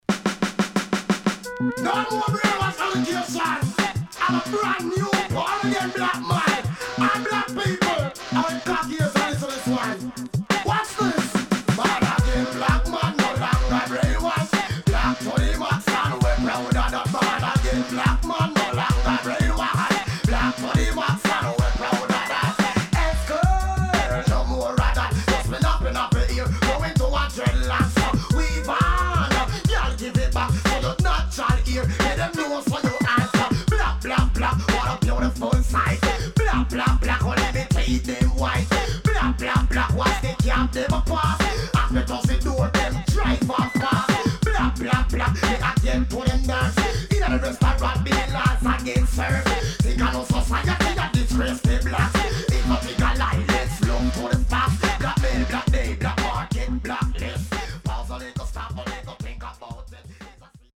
HOME > Back Order [DANCEHALL 7inch]
Hip Hop調
SIDE A:少しチリノイズ入ります。